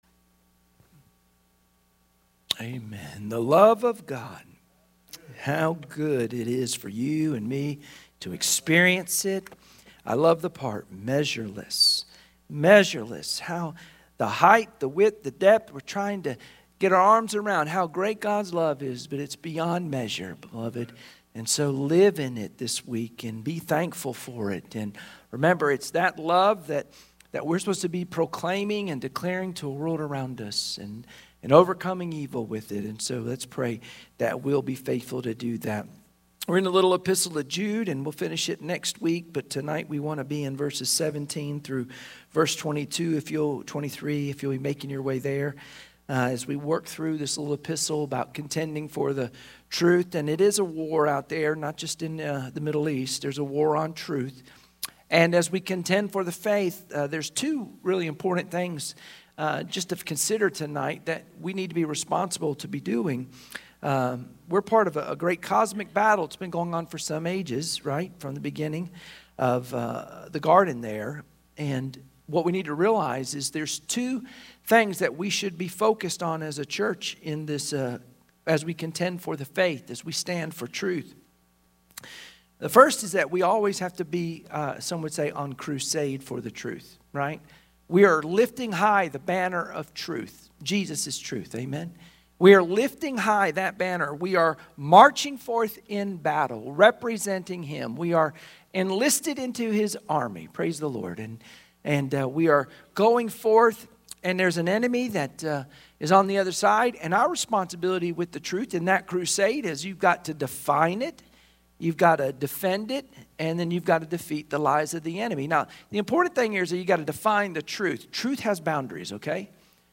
Sunday Evening Service Passage: Jude 17-23 Service Type: Sunday Evening Worship Share this